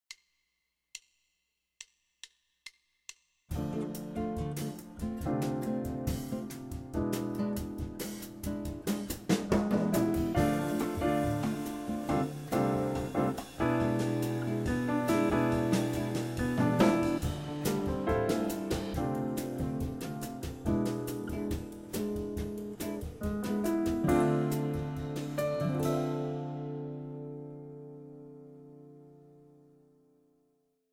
But I also tried it with Dm/C and it played fine there too. (by fine, I mean the guitar plays a nice syncopated pattern, not 4 quarter notes. In fact the guitar pushes into the bar, which is nice.
Dm Dm7/C E7/B
using the style _BNGGRMD (Med Bossa w/Piano NY)
BossaDemo23.WMA